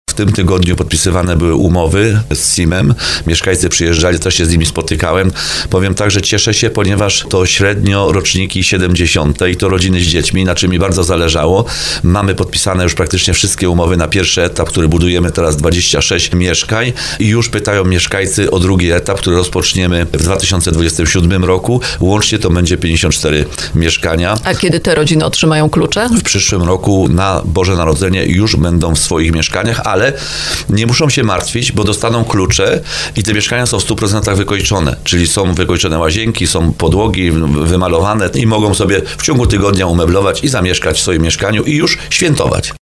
Jak mówił w programie Słowo za Słowo burmistrz Paweł Augustyn, każdy lokal oddany zostanie w standardzie 'pod klucz”.